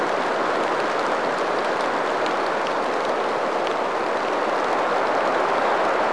Rain1.wav